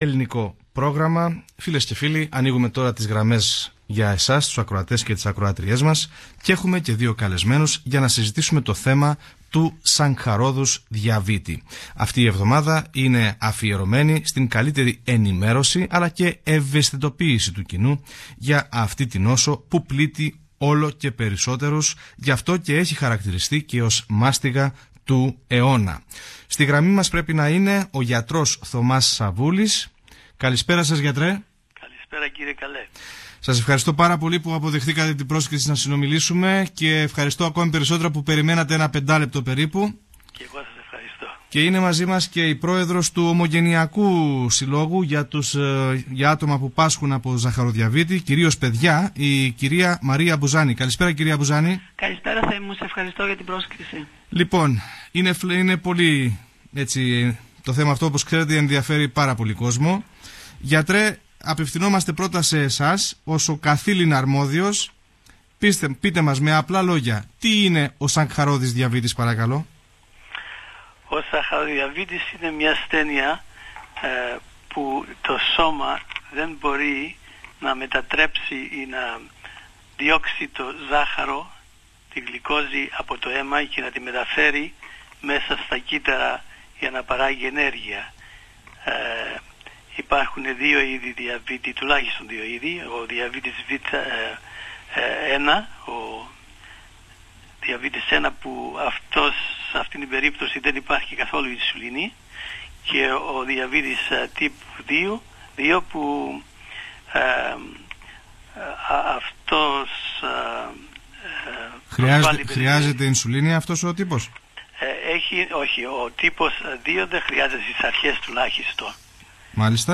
In the discussion listeners provide their comments and questions.